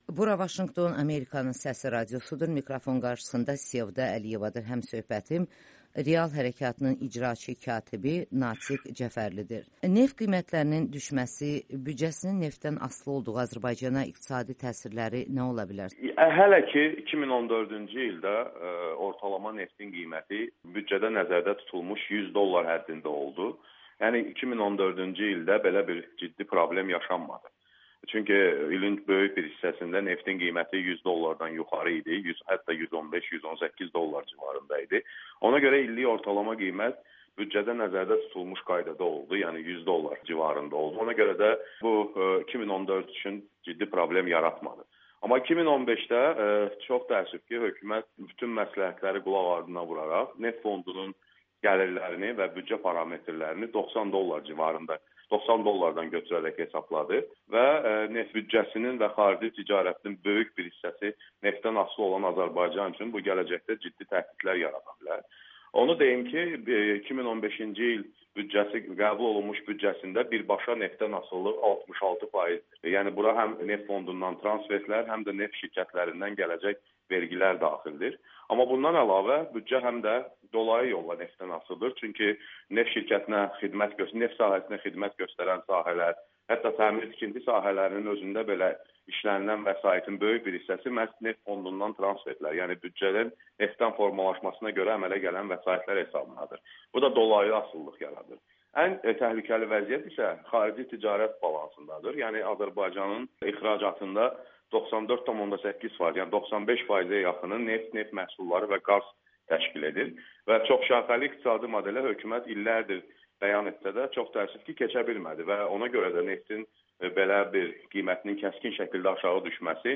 Neftin qiymətinin düşməsi Azərbaycan üçün ciddi fəsadlar yarada bilər [Audio-Müsahibə